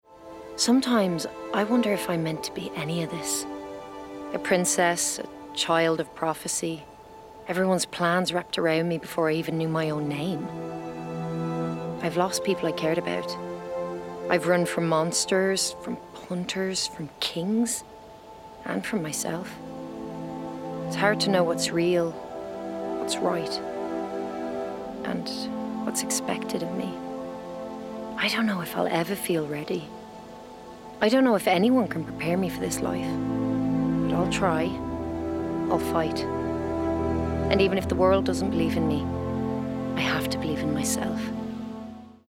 20/30's Neutral/RP,
Husky/Natural/Engaging
Determined Princess (Irish) Cunning Antagonist (American) Scientist (American)